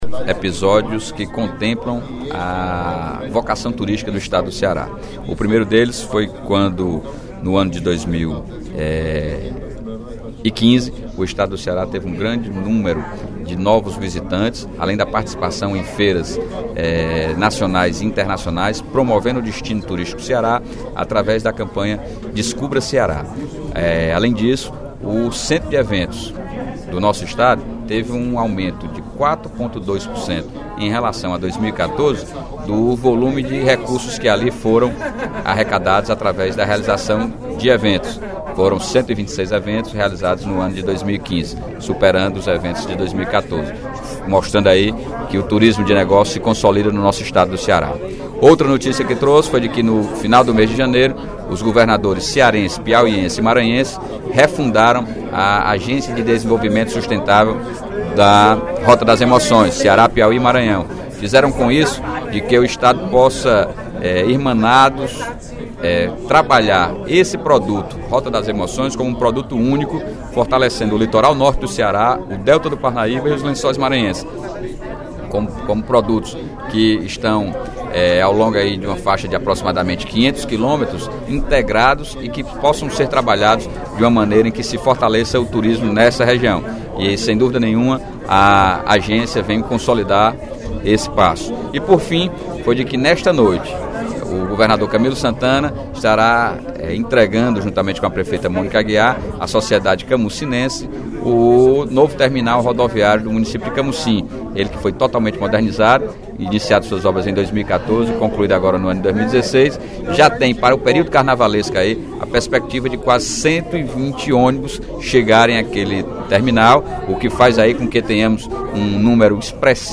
O deputado Sérgio Aguiar (Pros) abriu o primeiro expediente da sessão plenária desta quarta-feira (03/02) exaltando o reforço que o turismo cearense recebeu em 2015, por meio de investimentos do Governo do Estado e campanhas publicitárias.